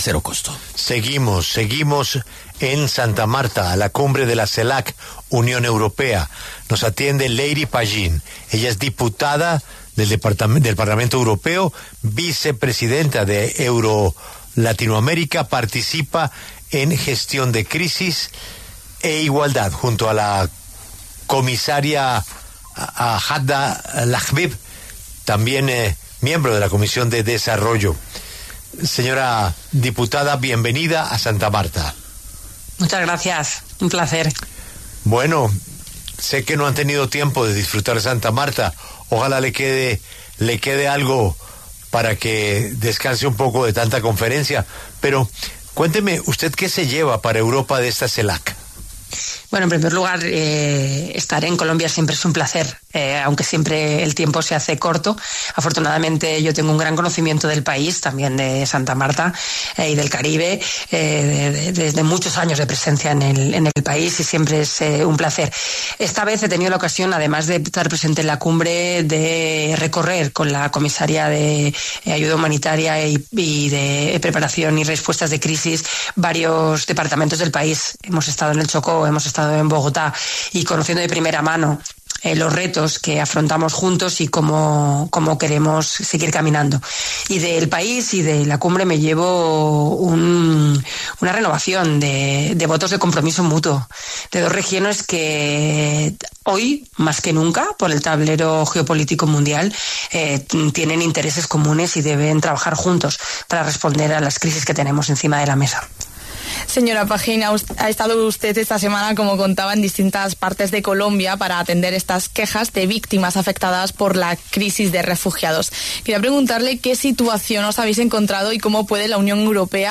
Leire Pajín, diputada del Parlamento Europeo y vicepresidenta de la Delegación en la Asamblea Parlamentaria Euro-Latinoamericana, habló en La W sobre la Cumbre CELAC-UE.